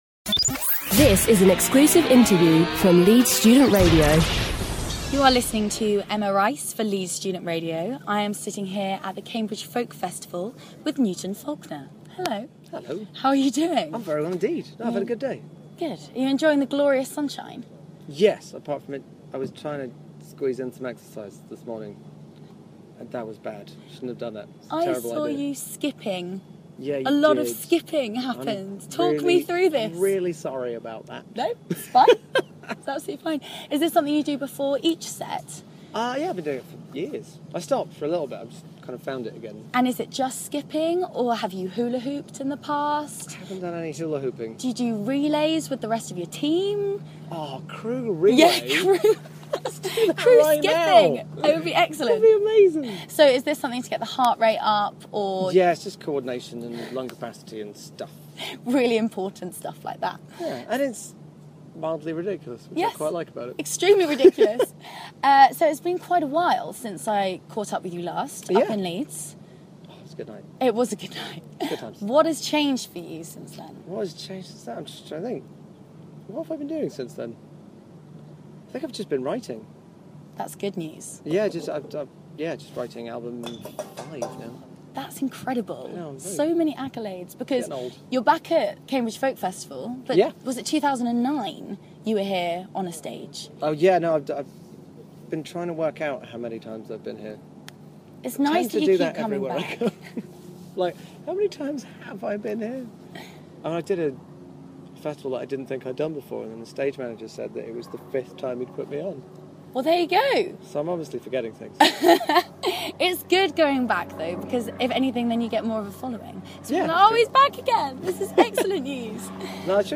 If you've never been to the Cambridge Folk Festival, then you're missing out.